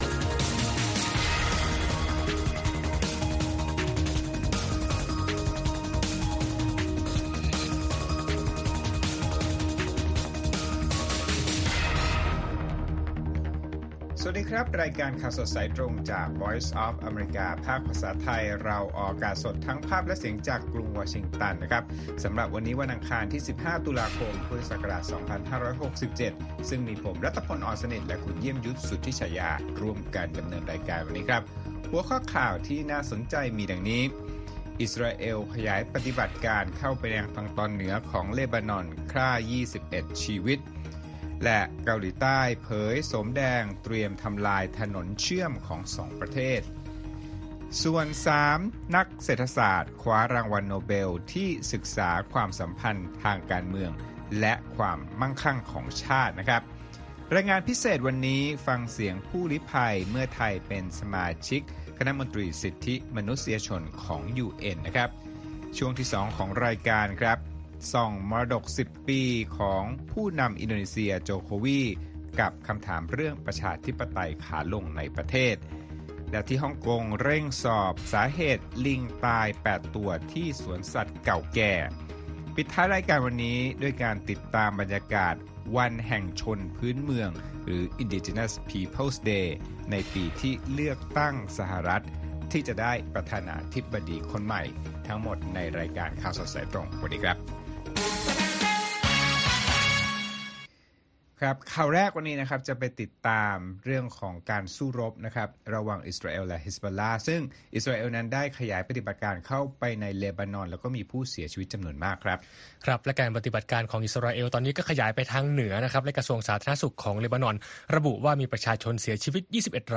ข่าวสดสายตรงจากวีโอเอ ไทย ประจำวันอังคารที่ 15 ตุลาคม 2567